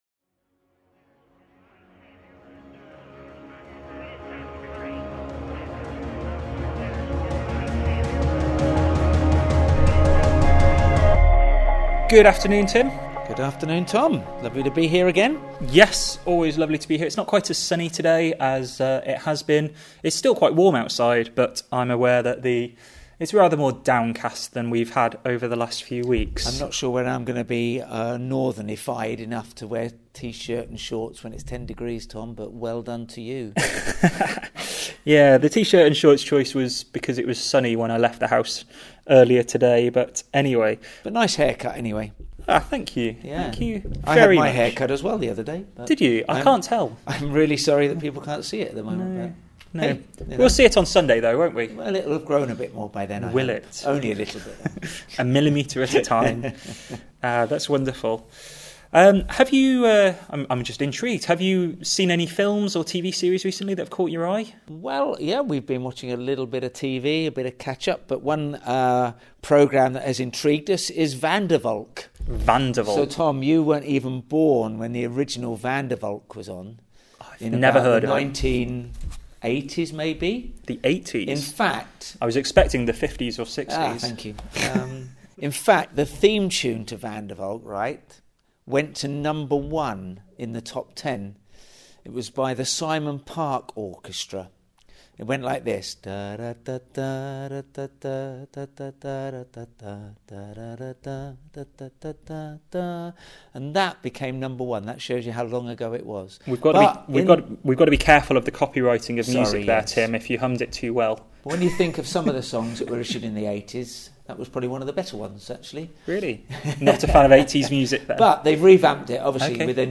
For the next six weeks we will be continuing to explore '40 Days with Jesus' in our weekly chat, focussing on some of the encounters people had with the risen Jesus.